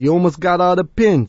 l_ehniceshotalmostall.wav